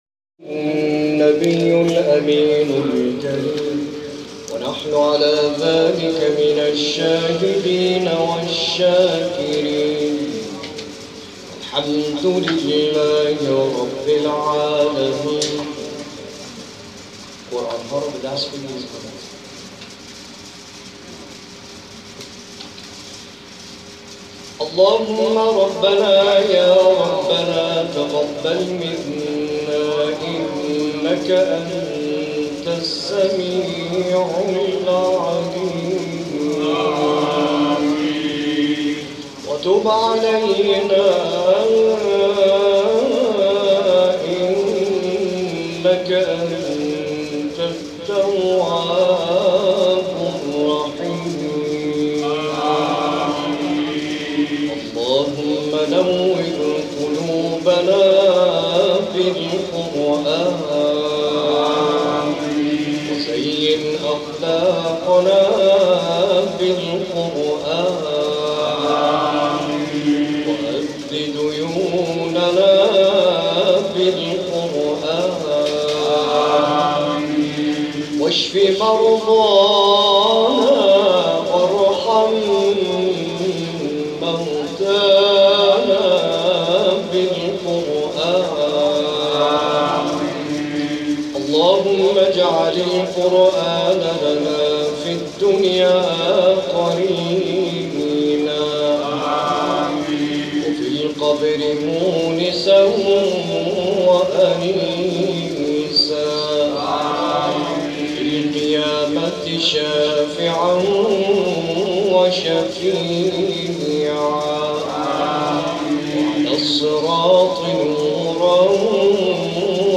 یادی از آتش‌نشان در جلسه قرآن
دعای ختم جلسه